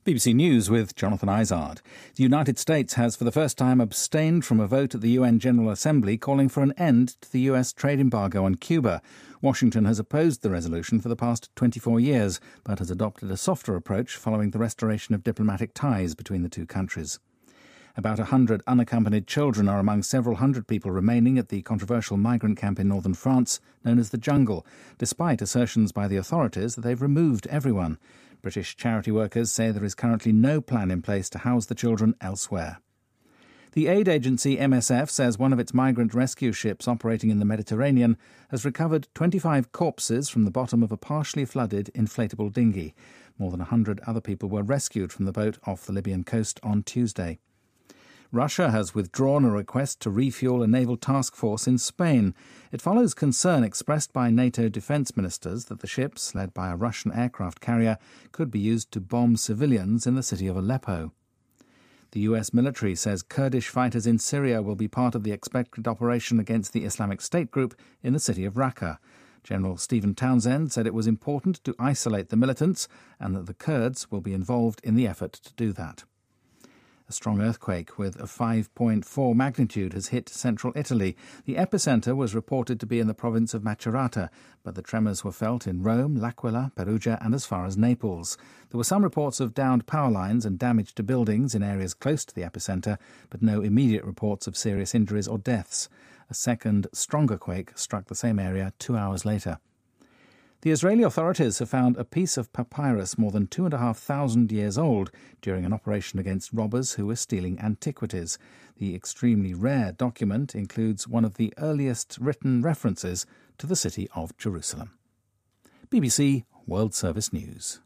BBC news,意大利发生5.4级地震